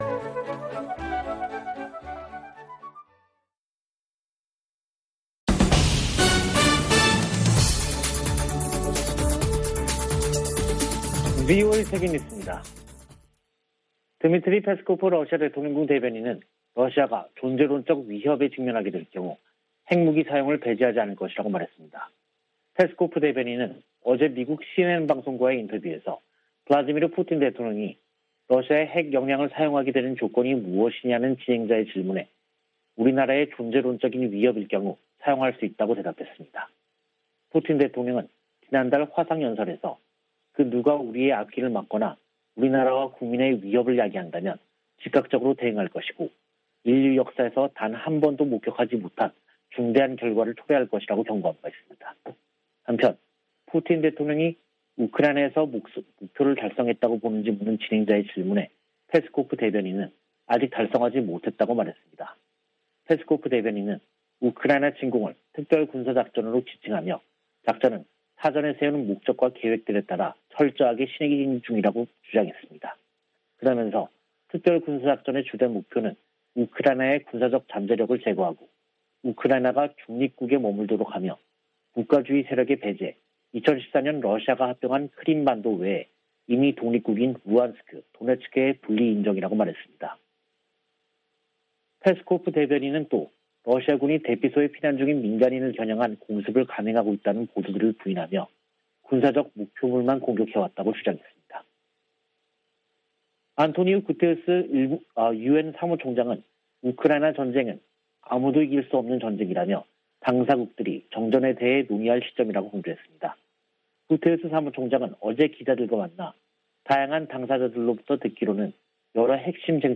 VOA 한국어 간판 뉴스 프로그램 '뉴스 투데이', 2022년 3월 23일 3부 방송입니다. 북한 해킹 조직이 러시아 등 사이버 범죄자들과 협력하고 있다고 백악관 국가안보보좌관이 지적했습니다. 독자 대북제재를 강화하고 있는 조 바이든 미국 행정부는 지난 3개월간 20건이 넘는 제재를 가했습니다. 유엔 인권기구가 49차 유엔 인권이사회 보고에서 회원국들에 국내 탈북민들과 접촉하고 인권 유린 책임을 규명할 수 있게 보장해 줄 것을 촉구했습니다.